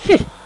Hmmph! Sound Effect
hmmph.mp3